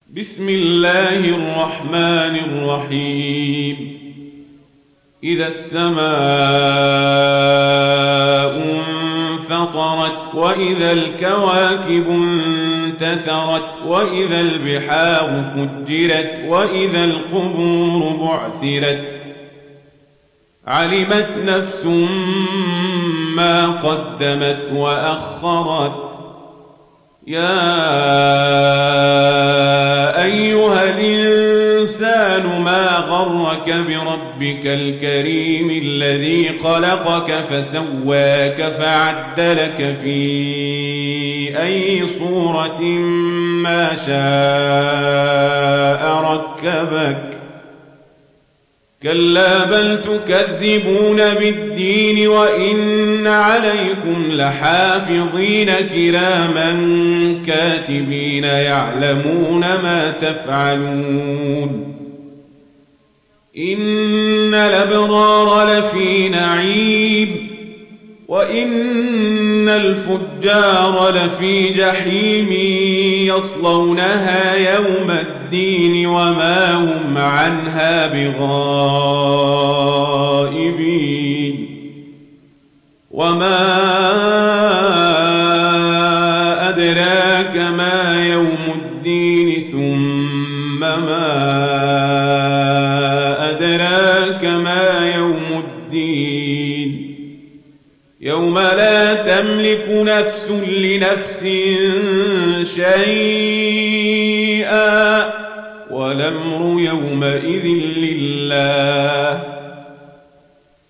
تحميل سورة الانفطار mp3 بصوت عمر القزابري برواية ورش عن نافع, تحميل استماع القرآن الكريم على الجوال mp3 كاملا بروابط مباشرة وسريعة